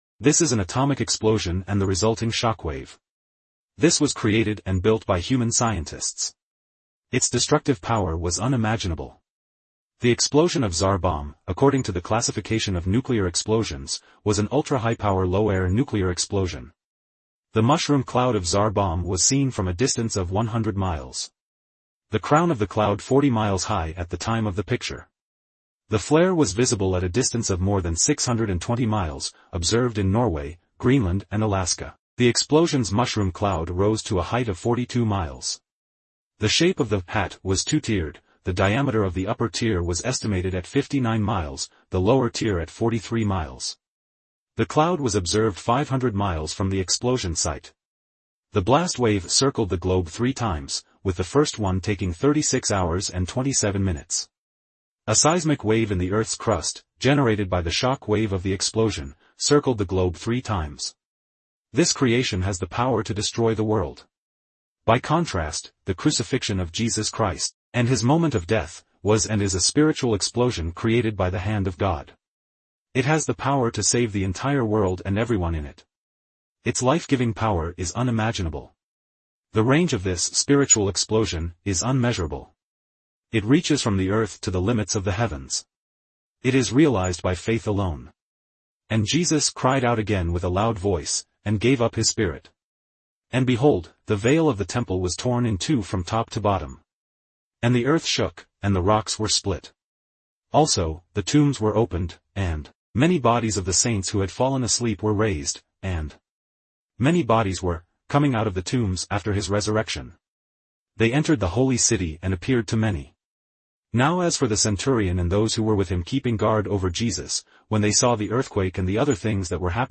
This is an atomic explosion and the resulting shockwave.